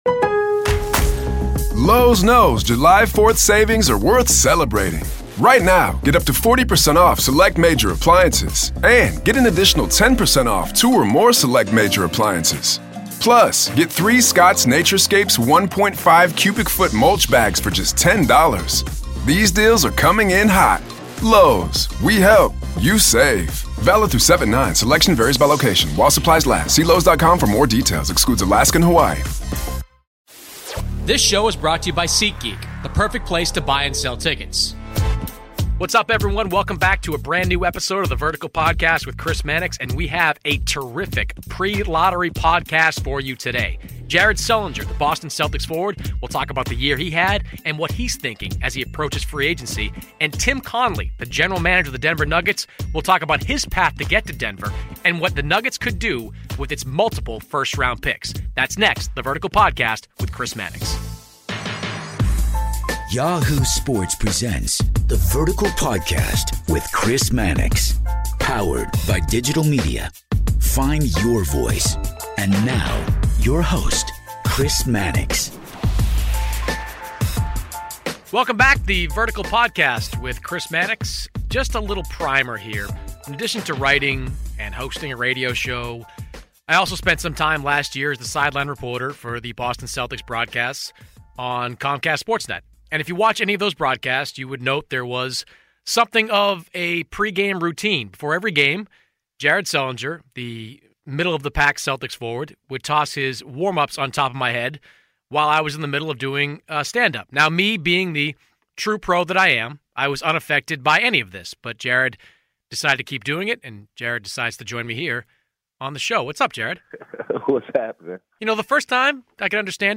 Boston Celtics power forward Jared Sullinger joins The Vertical this week to discuss his season with the Celtics and what it was like playing under head coach Brad Stevens.